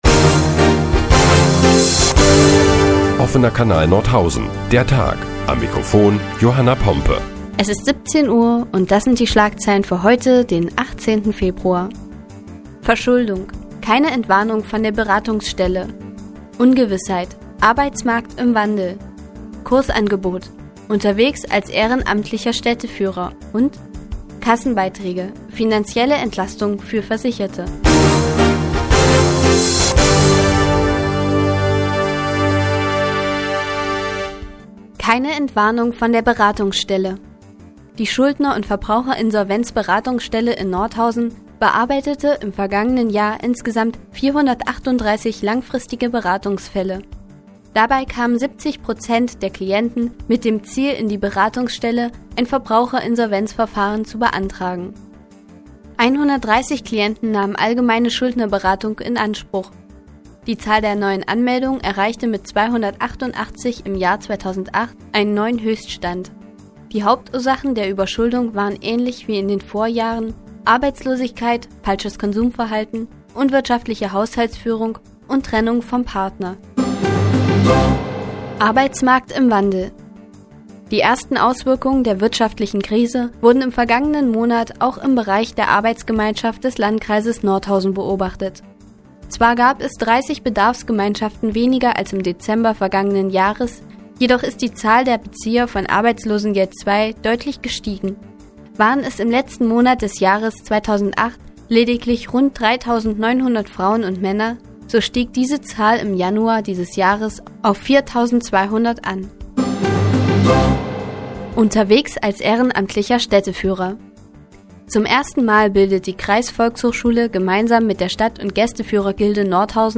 Die tägliche Nachrichtensendung des OKN ist nun auch in der nnz zu hören. Heute geht es unter anderem um die Entwicklung am Arbeitsmarkt und finanzielle Entlastungen für Versicherte.